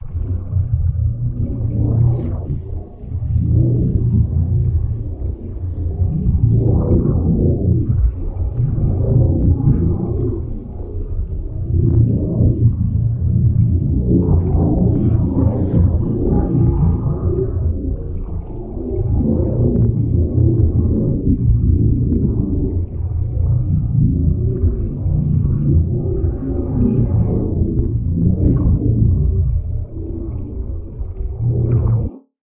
sphere_idle.ogg